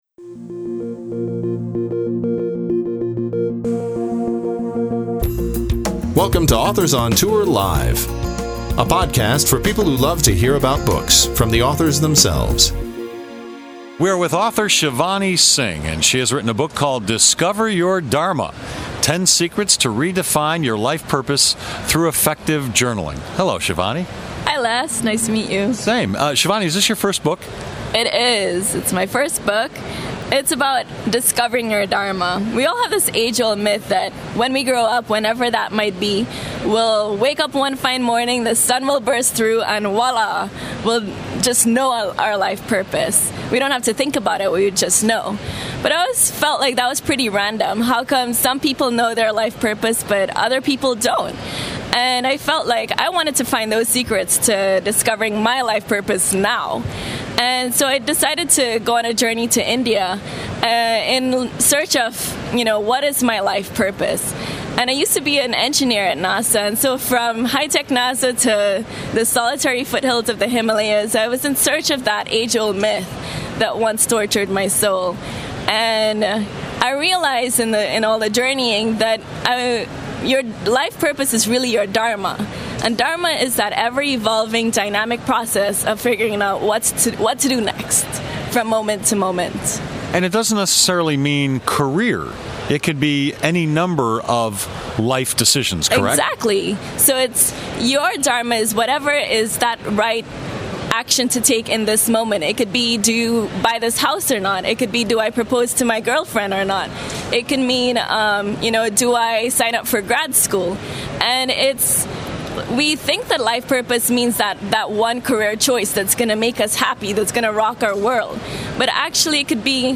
This podcast was recorded at BookExpo America 2010 in New York City.